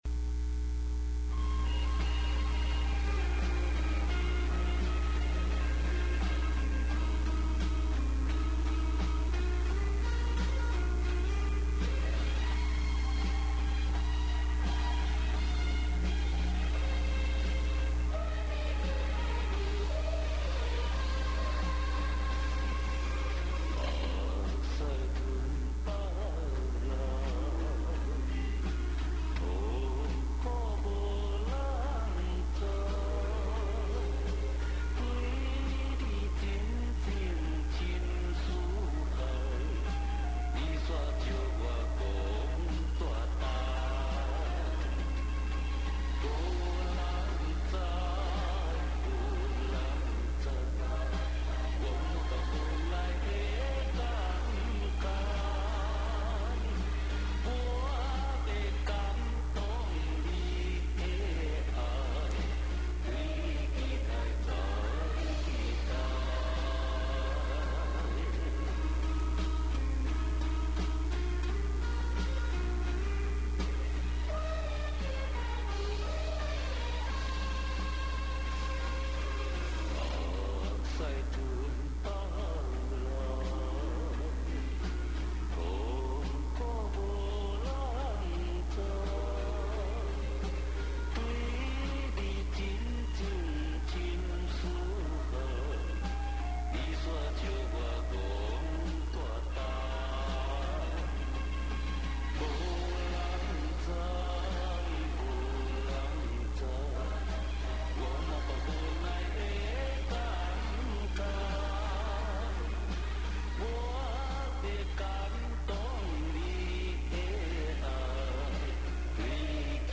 MP3華語流行樂合輯DVD版